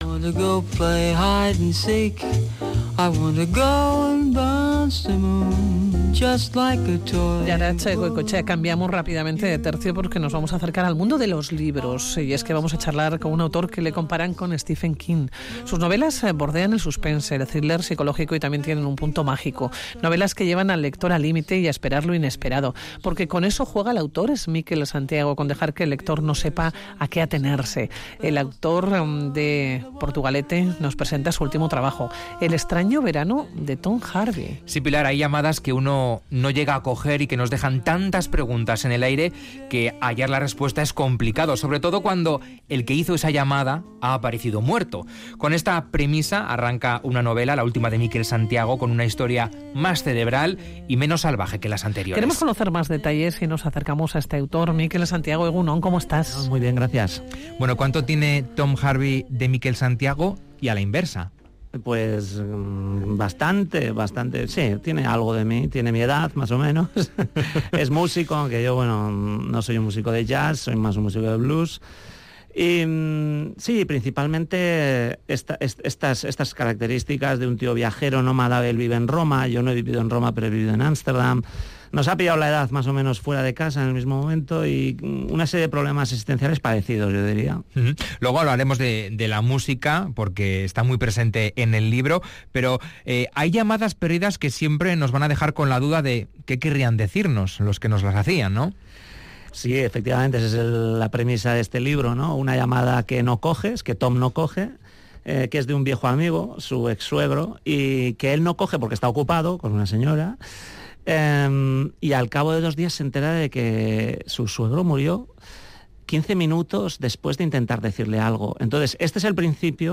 Mikel Santiago presenta su última movela en Radio Vitoria